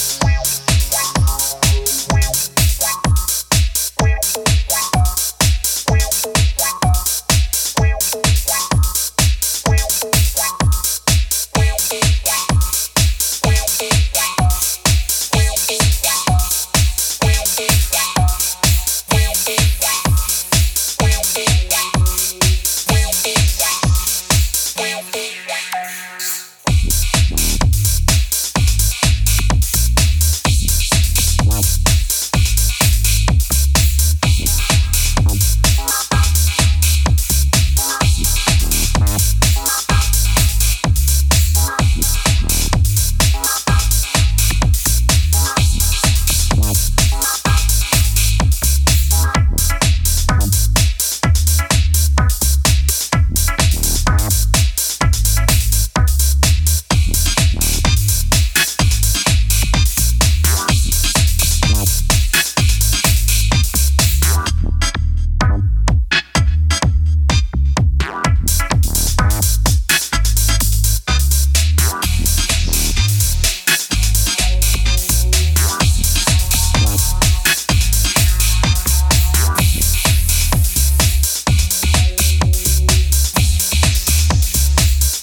techno. With pulsating beats and intricate soundscapes